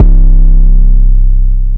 808 14 [ wheezy ].wav